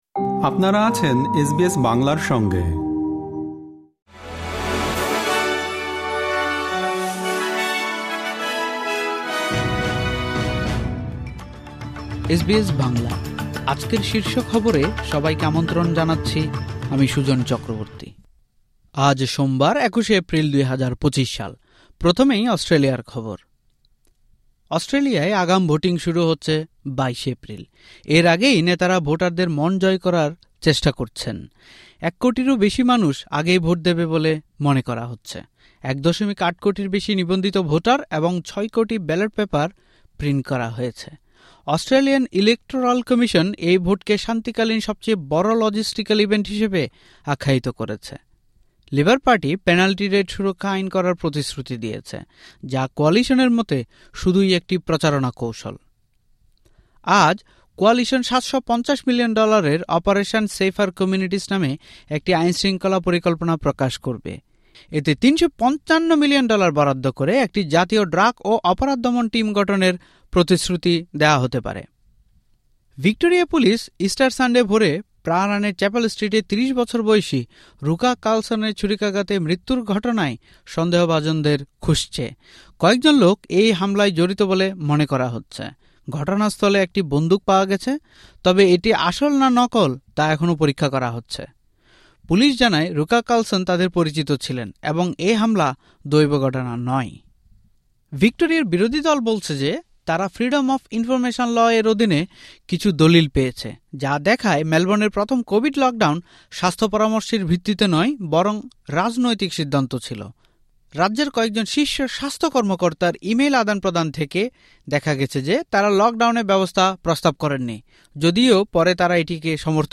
এসবিএস বাংলা শীর্ষ খবর: ২১ এপ্রিল, ২০২৫